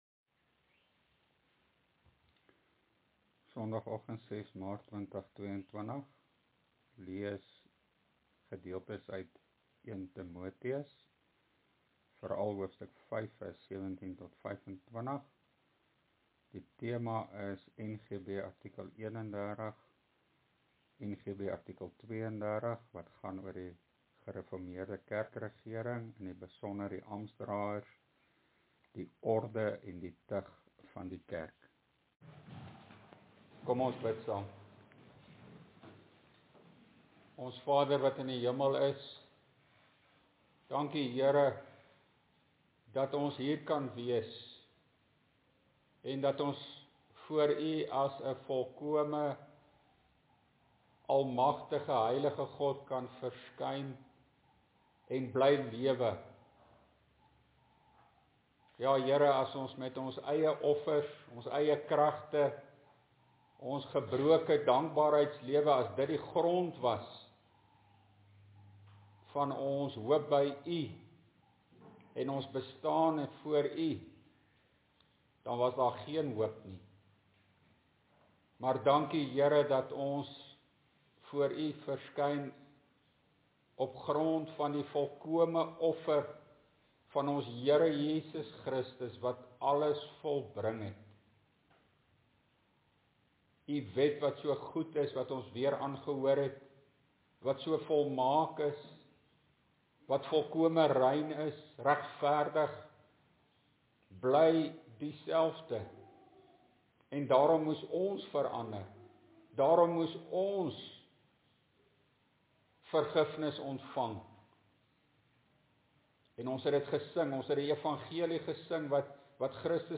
(Preekopname: GK Carletonville, 2022-03-06 , nota: let wel, die inhoud van die preek en teksnotas hier onder stem nie altyd ooreen nie, die notas is nie volledig nie, die audio preek is die volledige preek).